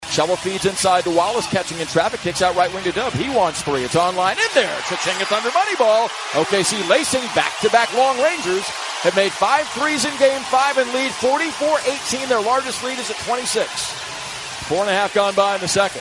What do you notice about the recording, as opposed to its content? Thunder basketball airs on Sports Talk 99.1 FM - KPGM in NE Oklahoma.